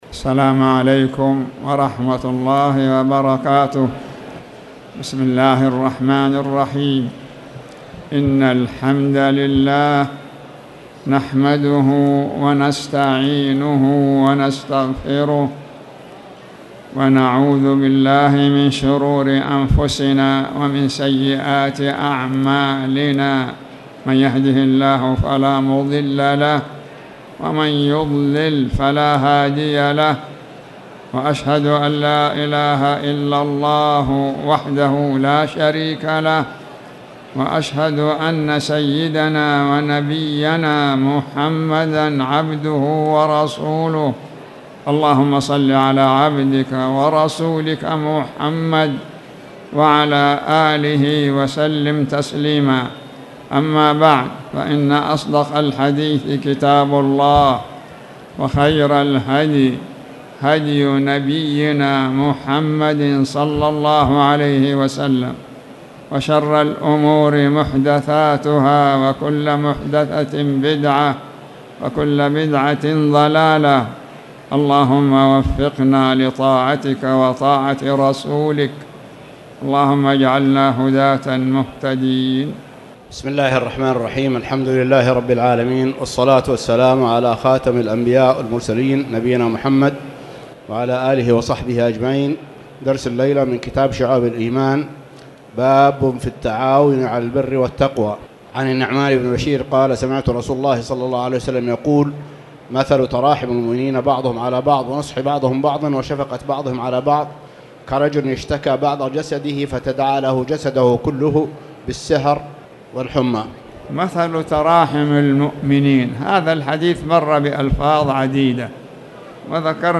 تاريخ النشر ٦ شعبان ١٤٣٨ هـ المكان: المسجد الحرام الشيخ